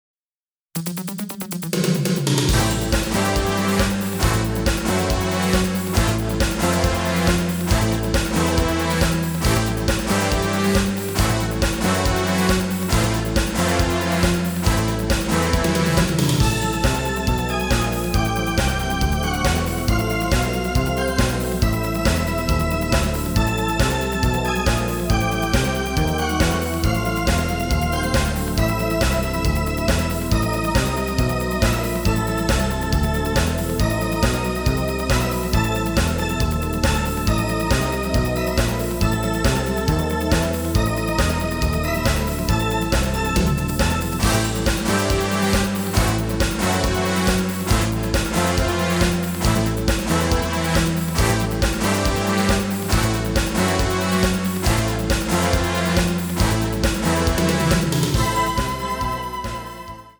” specially re-orchestrated and arranged for that album